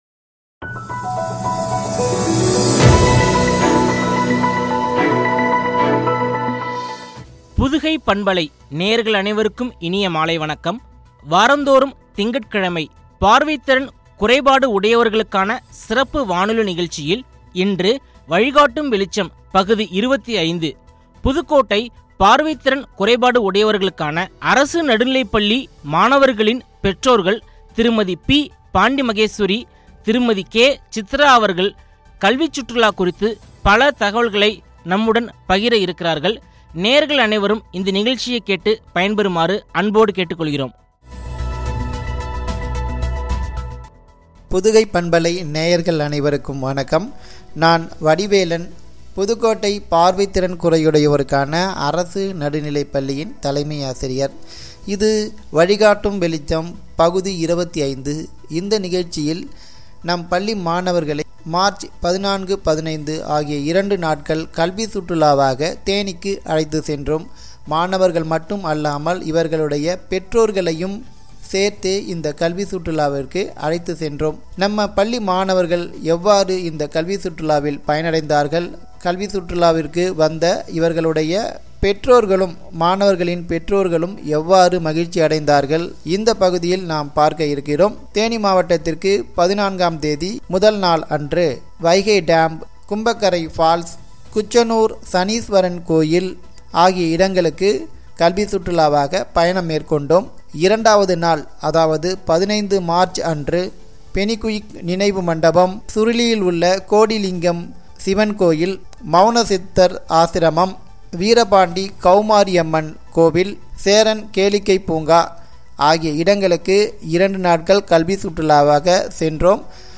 பார்வை திறன் குறையுடையோருக்கான சிறப்பு வானொலி நிகழ்ச்சி
உரையாடல்